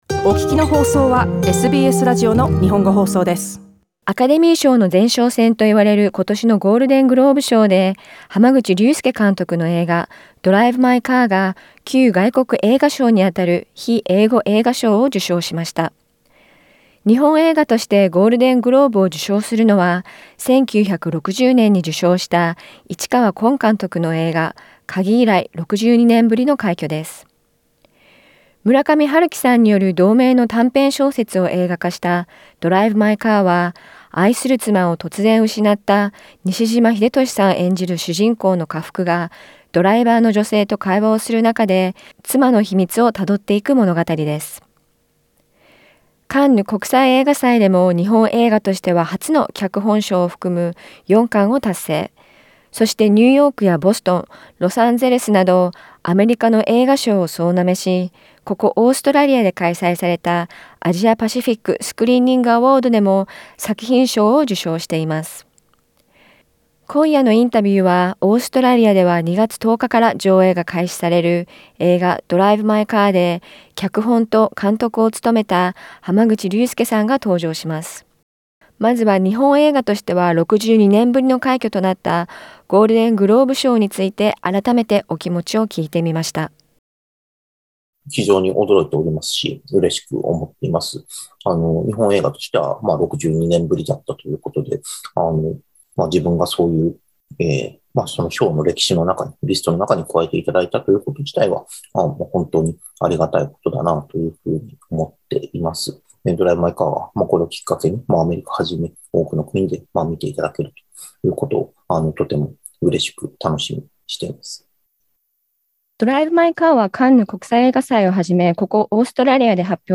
日本映画として62年ぶりとなったゴールデングローブ受賞の『ドライブ・マイ・カー』でメガホンを握った濱口竜介監督にお話を聞きました。